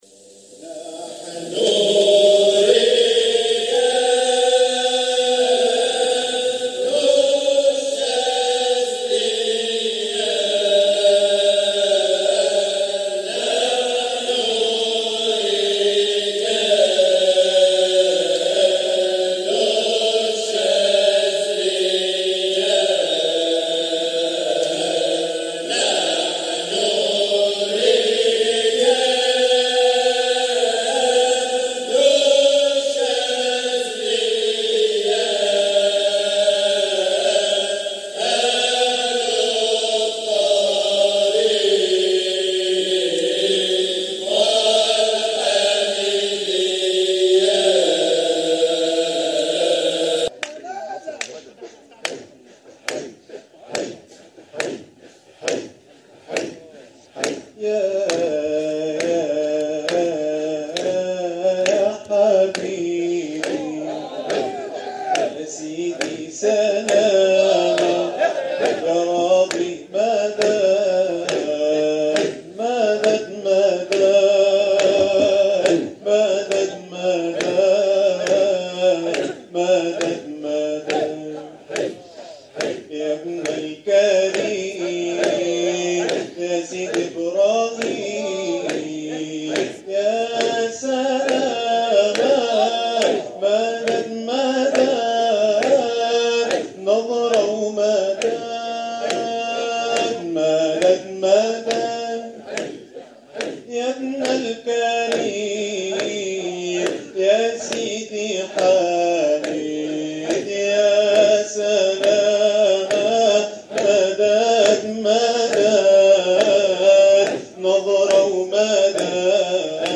جزء من حلقة ذكر فى 9 رمضان 1444 هـ ج2